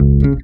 INC BEL1.wav